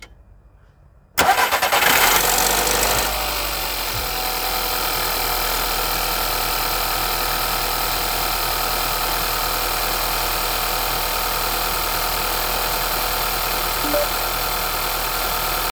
U studeného tam slyším drobné zachrastění asi tak v první půlvteřině, ale to mi přijde celkem v normě vzhledem ke konstrukci motoru.
Motor 1.2 TSI CBZB
Možná i nějaká kladka je slyšet, jak kontinuálně šustí.
Něco v tom studeném startu tam slyšet je.
Nic drastického.